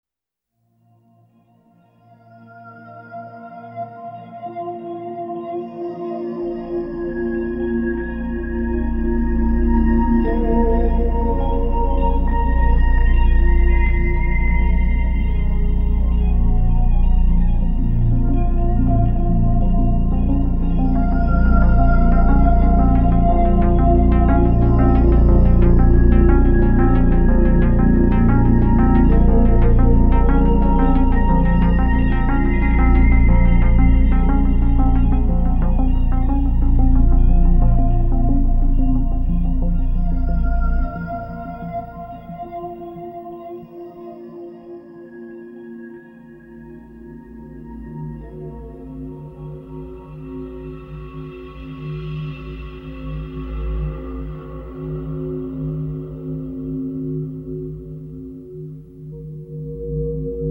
una hermética partitura intimista